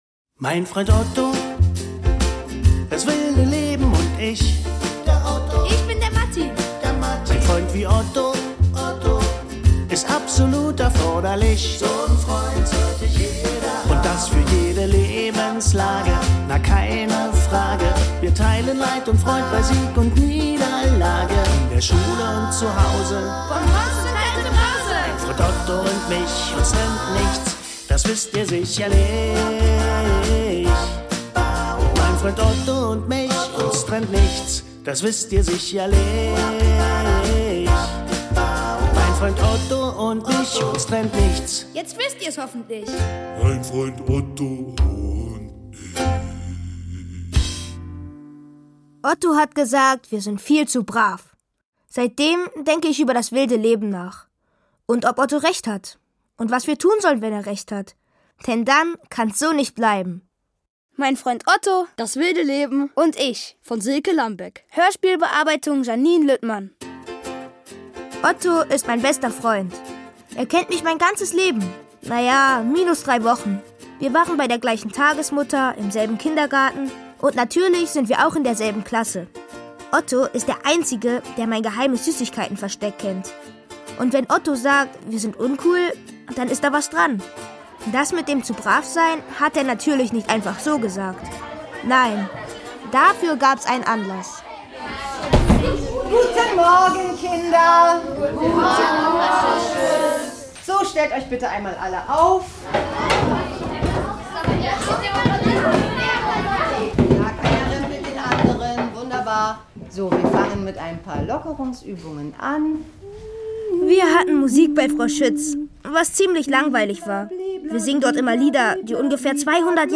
Kinderhörspiel: Mein Freund Otto, das wilde Leben und ich